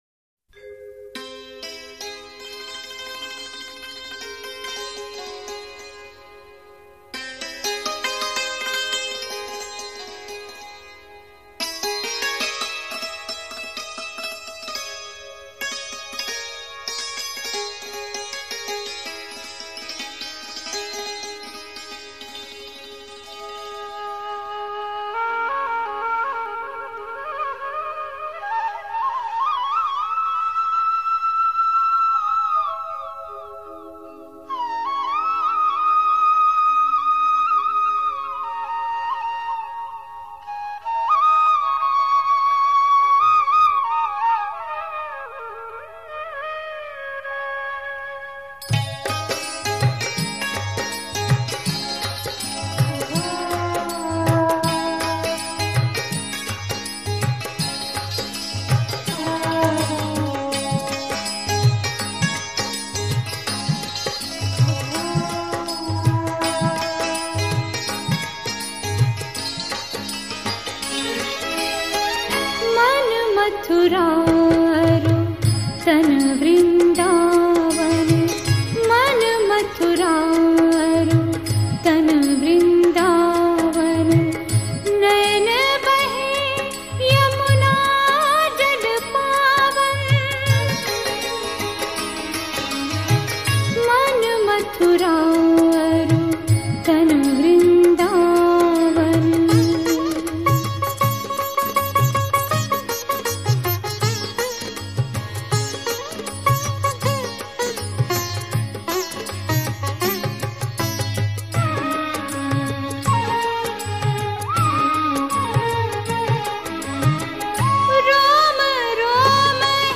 KrishnaBhajans01.mp3